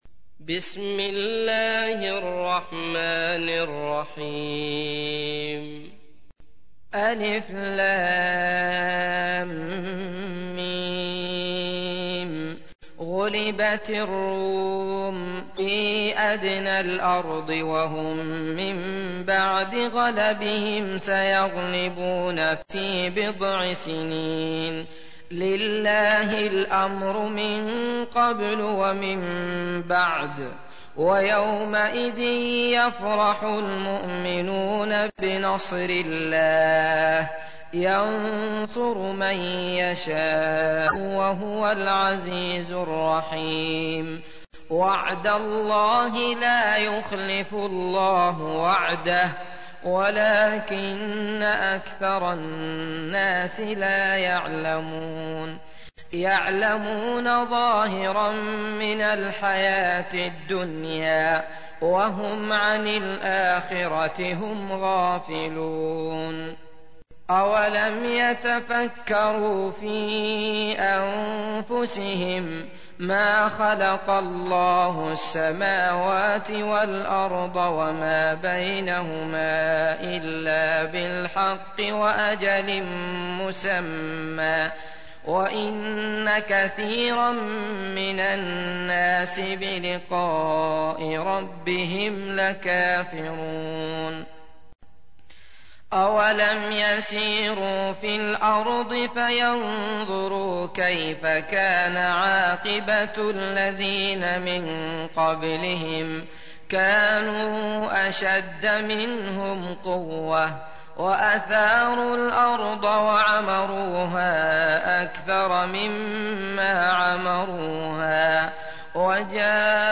تحميل : 30. سورة الروم / القارئ عبد الله المطرود / القرآن الكريم / موقع يا حسين